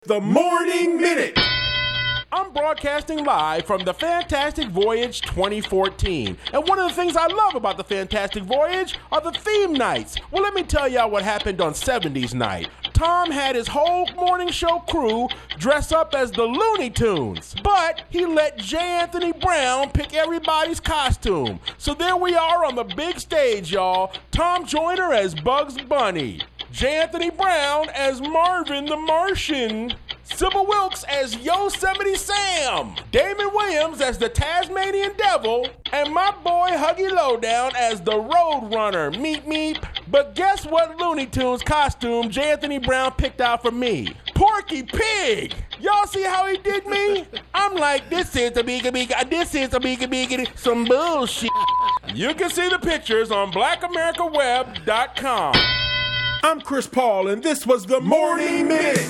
From The Fantastic Voyage Cruise 2014